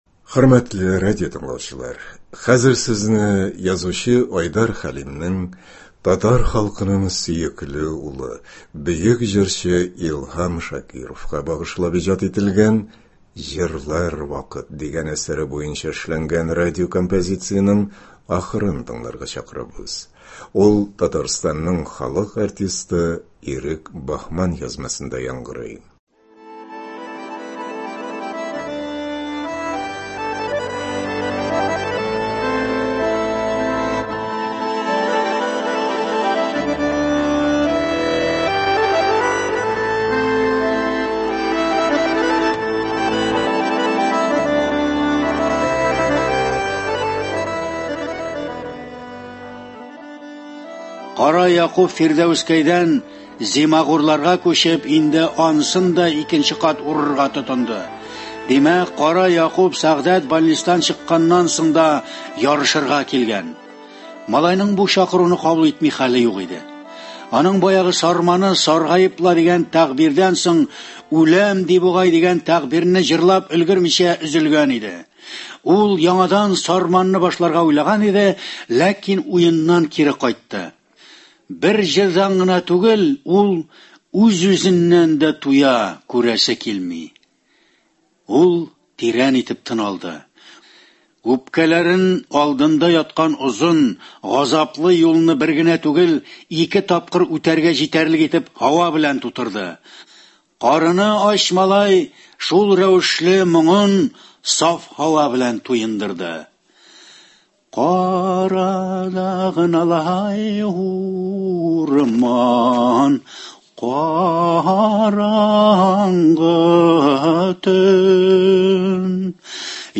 Айдар Хәлим. “Җырлар вакыт”. Әдәби композиция.